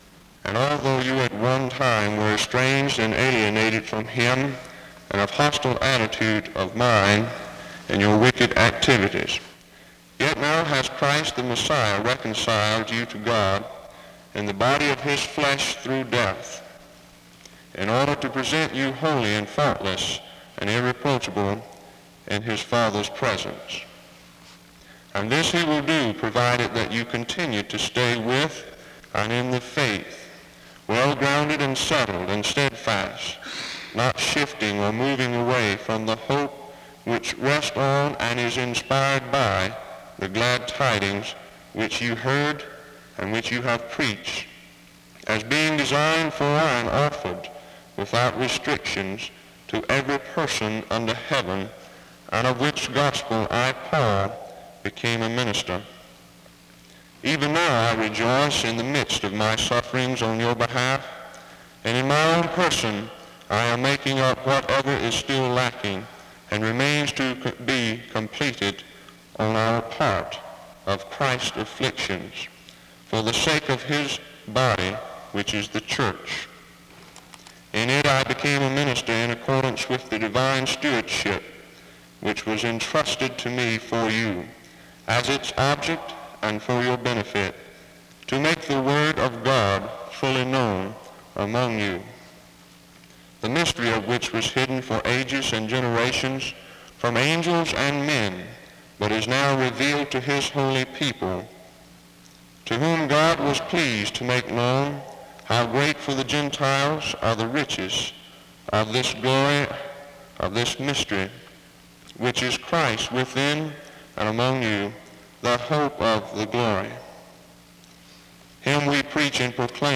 SEBTS_Chapel_Student_Service_1969-03-18.wav